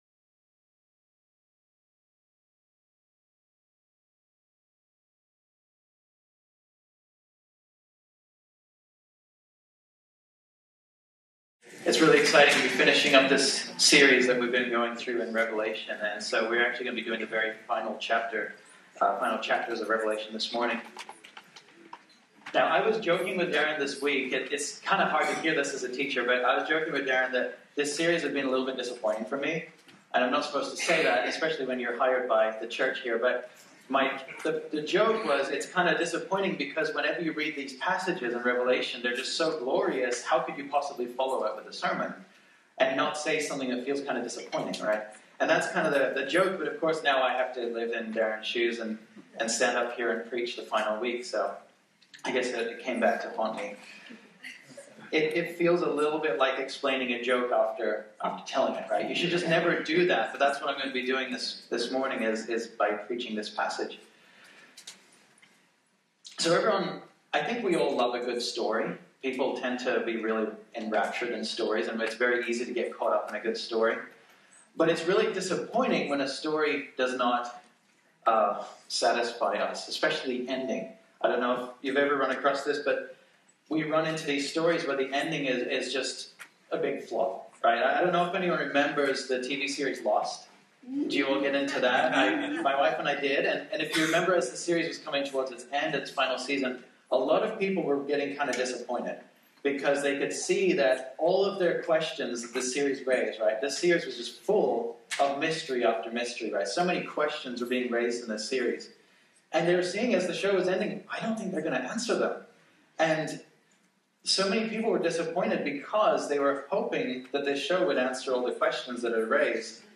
Listen to preach on Revelation 22 as part of our sermon series called WORTHY. This sermon was originally preached on Sunday, April 28, 2019.